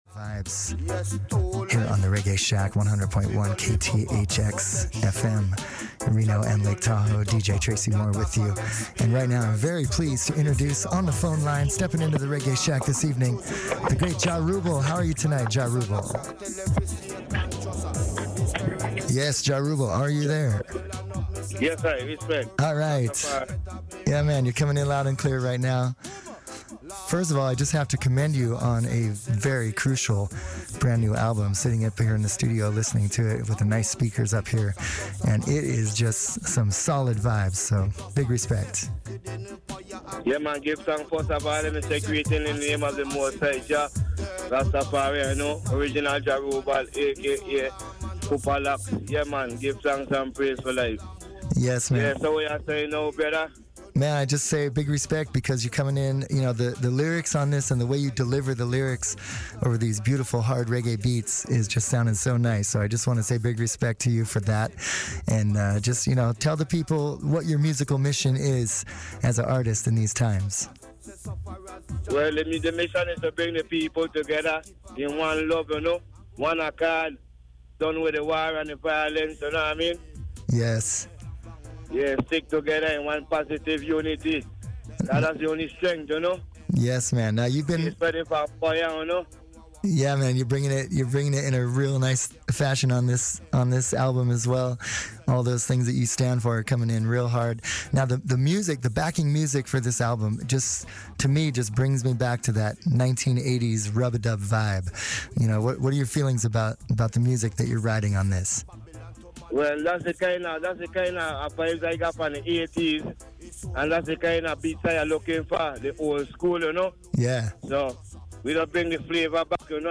Reggae Shack Interview